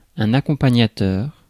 Ääntäminen
Synonyymit guide Ääntäminen France: IPA: /a.kɔ̃.pa.ɲa.tœʁ/ Haettu sana löytyi näillä lähdekielillä: ranska Käännös Konteksti Substantiivit 1. accompanier 2. accompanist musiikki Suku: m .